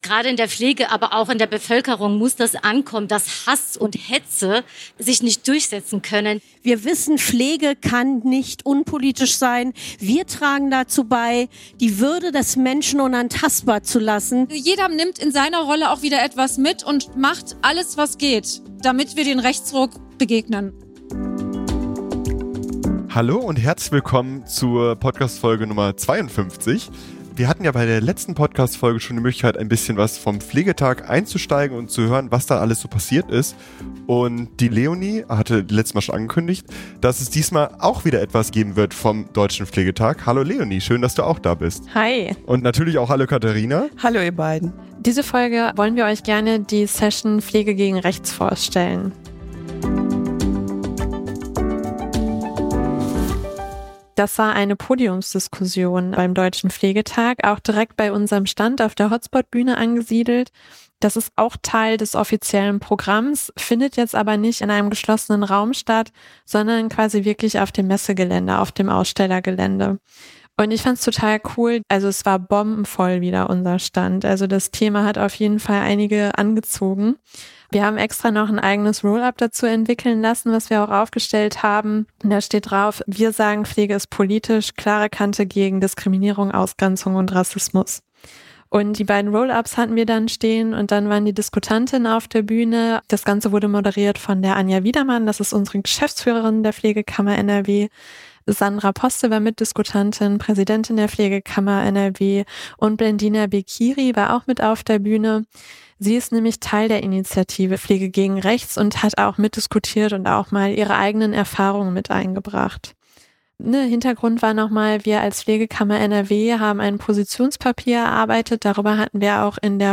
Sonderfolge Deutscher Pflegetag 2024 Podiumsdiskussion die Initiative „Pflege gegen Rechts“ ~ PflegeStärke Podcast